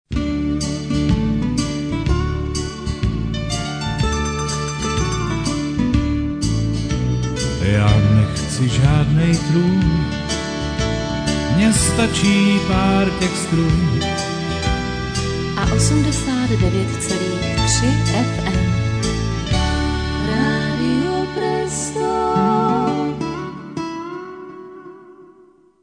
Jingle...